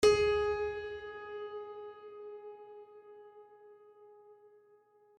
HardPiano
gs3.mp3